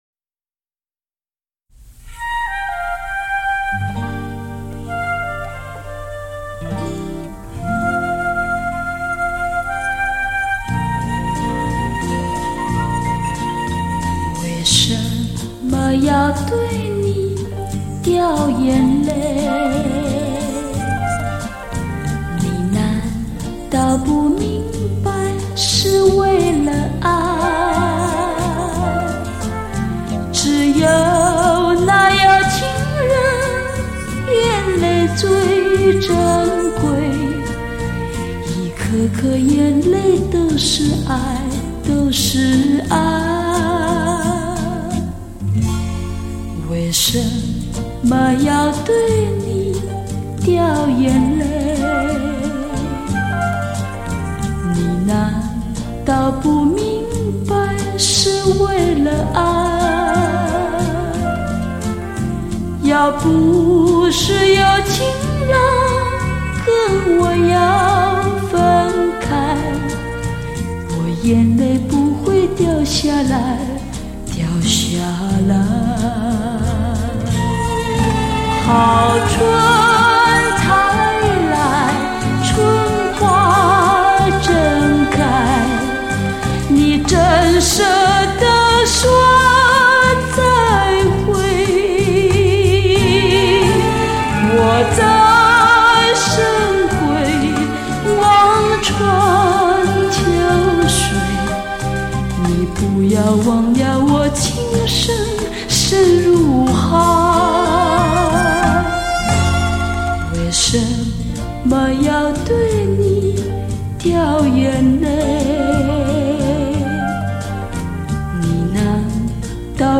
本专辑首次发行日期为1976年 当年录音技术为类比录音
为尊重当年的声音品质 不修饰、增加声音内容 全部原音重现